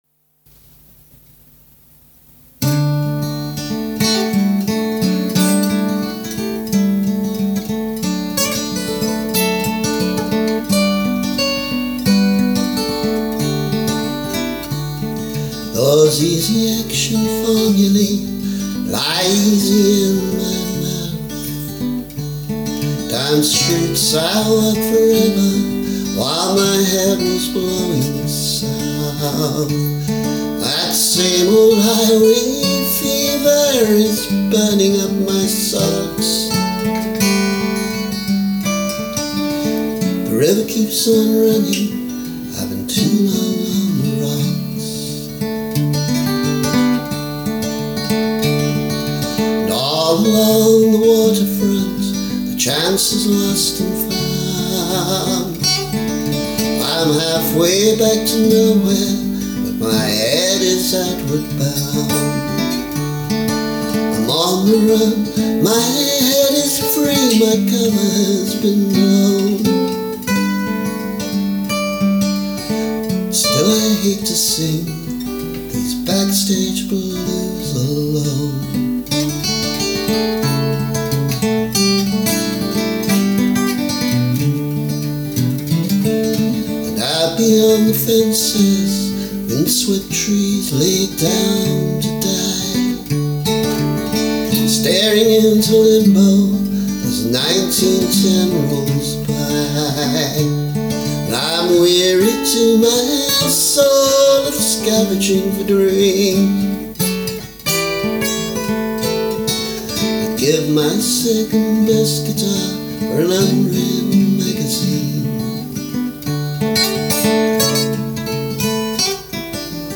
And experimenting with a different arrangement: